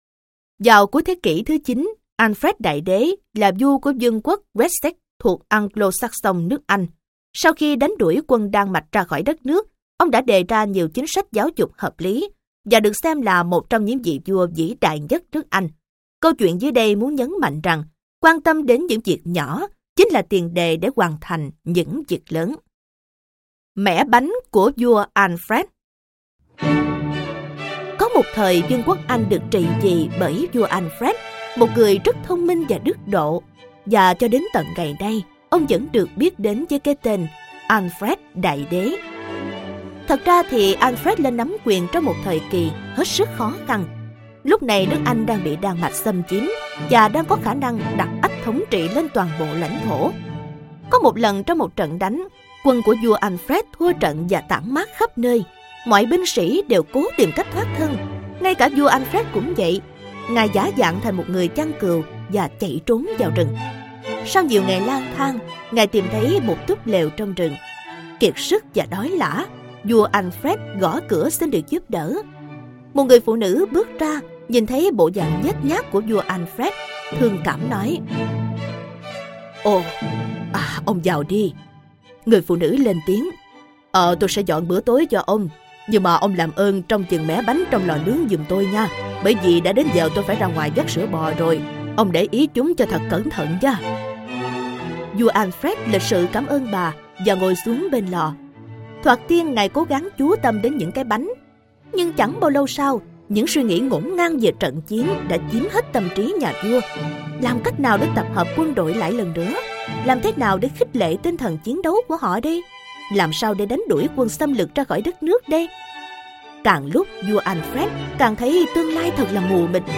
Sách nói | Tuyển tập chuyện ngắn hay Việt Nam dành cho thiếu nhi Tập 4 - 11